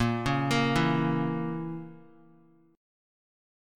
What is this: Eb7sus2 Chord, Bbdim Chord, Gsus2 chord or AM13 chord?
Bbdim Chord